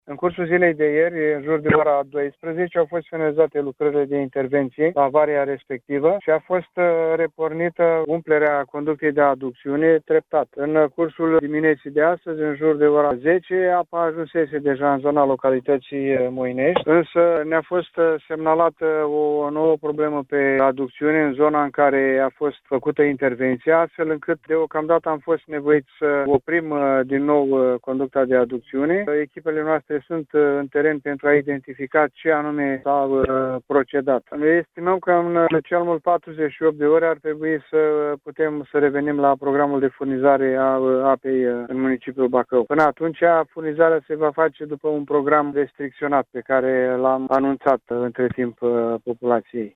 Contactat telefonic